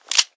revolverClose.ogg